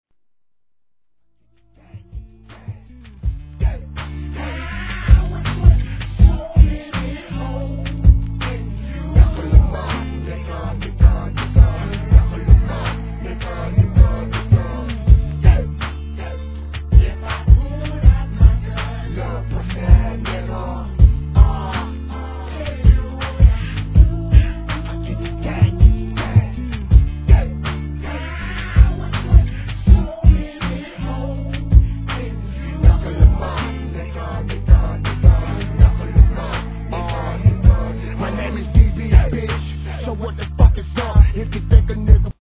HIP HOP/R&B
コンピュータが故障したような「ピロリロピロリロ」酔っ払った上音が鳴る中毒系バウンスにこの客演ですから。